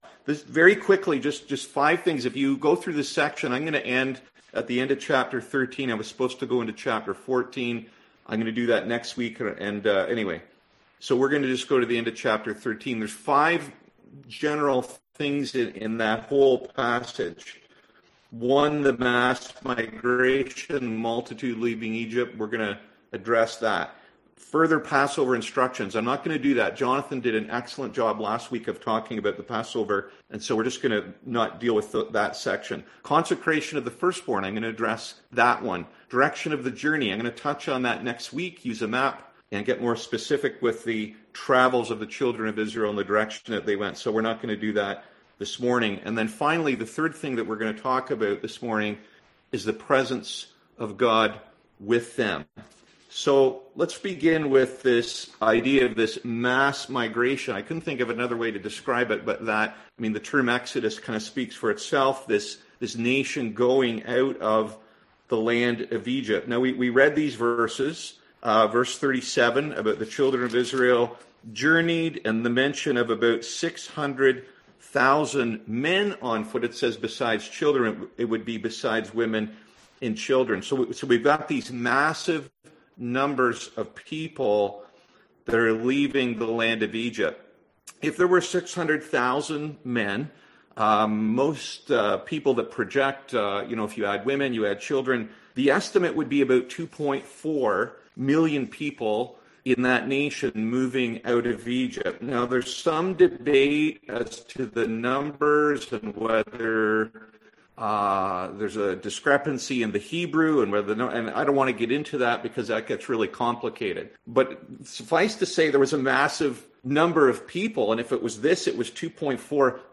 Passage: Exodus 12-13 Service Type: Sunday AM